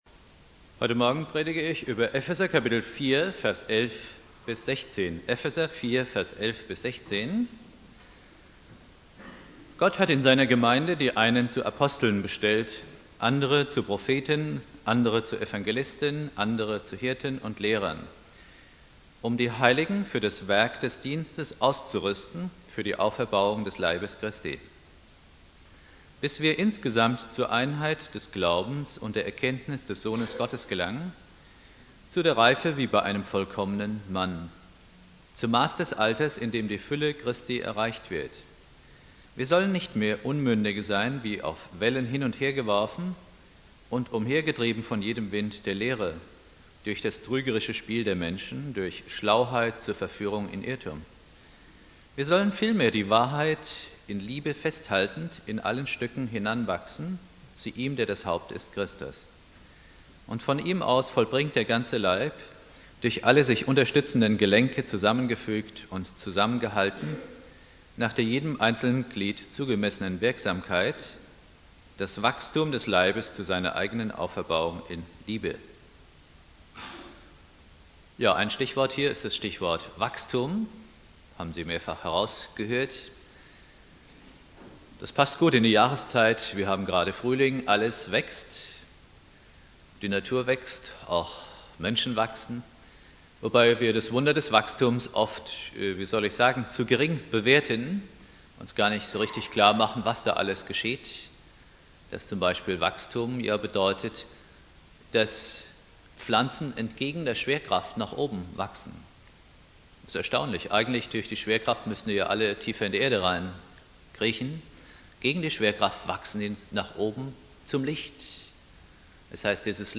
Predigt
Pfingstmontag